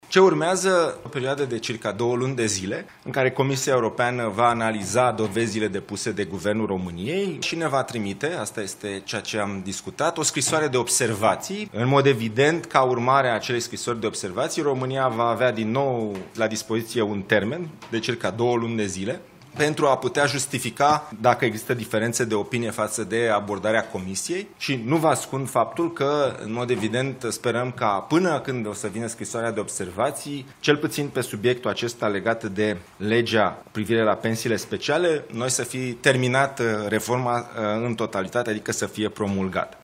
Ministrul Investițiilor și Proiectelor Europene, Dragoș Pîslaru: Sperăm ca legea privind pensiile speciale ale magistraților să fie promulgată până când Comisia Europeană ne va trimite scrisoarea de observații